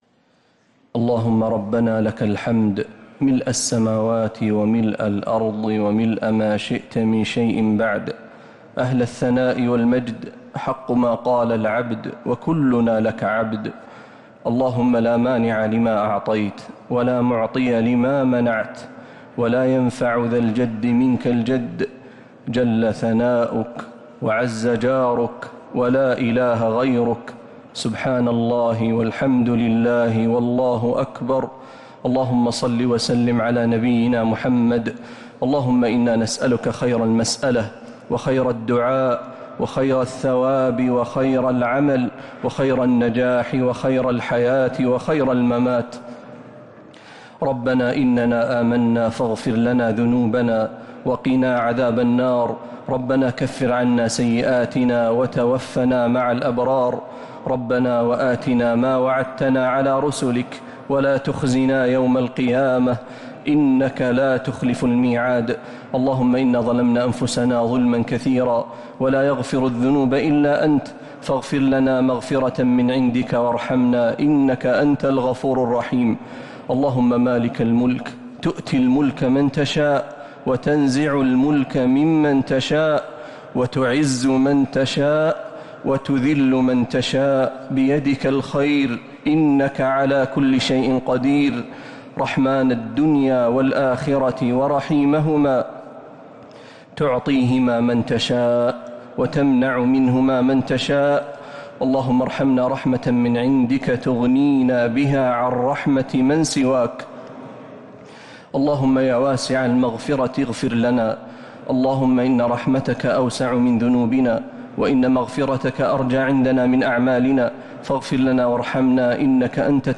دعاء القنوت ليلة 9 رمضان 1447هـ | Dua 9th night Ramadan 1447H > تراويح الحرم النبوي عام 1447 🕌 > التراويح - تلاوات الحرمين